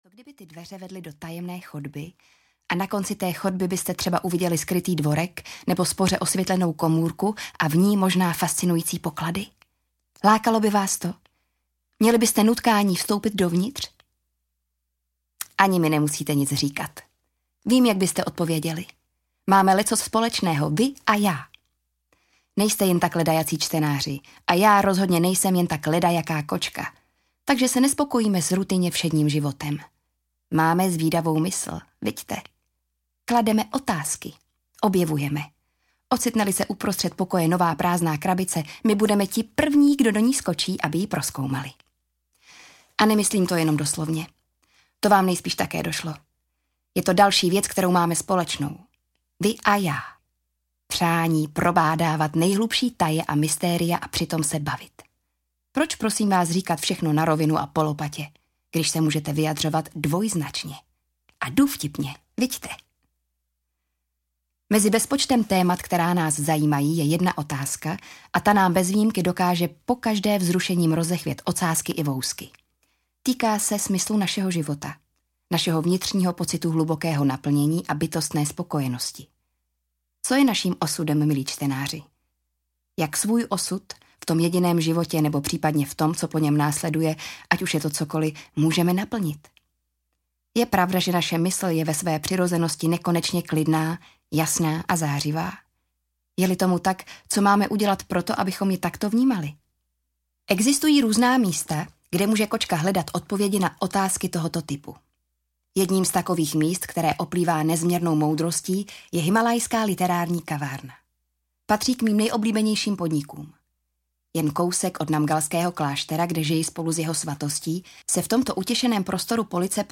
Dalajlamova kočka a čtyři tlapky duchovního úspěchu audiokniha
Ukázka z knihy